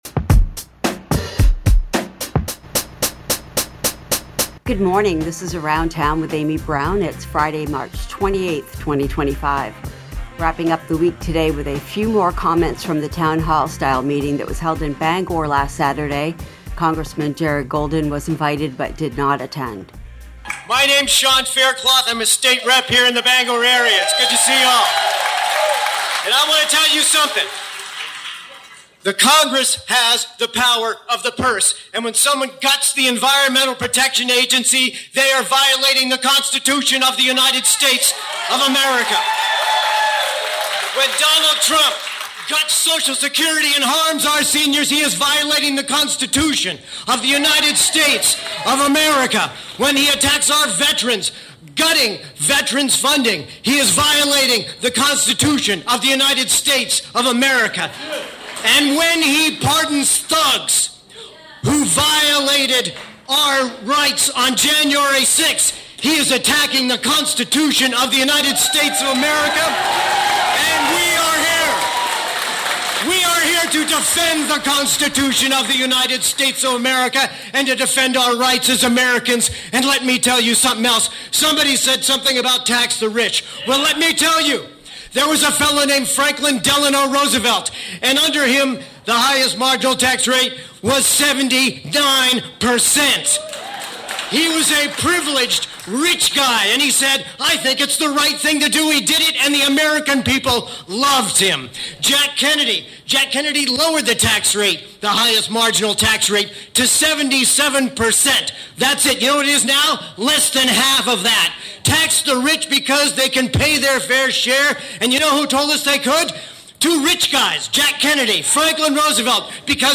Wrapping up the week today with a few more comments from the town hall style meeting held in Bangor last Saturday.